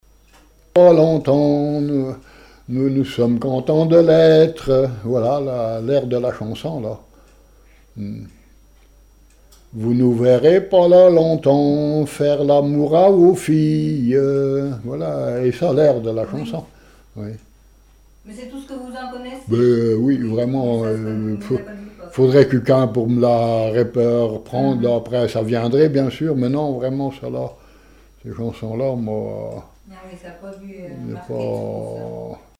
refrain de conscrits
Chants brefs - Conscription
gestuel : à marcher
Pièce musicale inédite